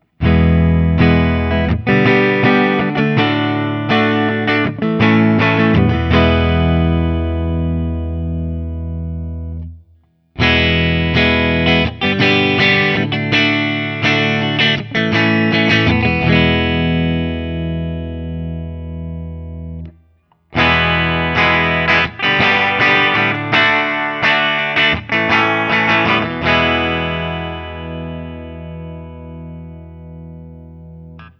Open Chords
I’ve had this guitar for some time and I had made the recordings using my Axe-FX II XL+ setup through the QSC K12 speaker recorded direct into my Macbook Pro using Audacity.
For the first few recordings I cycled through the neck pickup, both pickups, and finally the bridge pickup.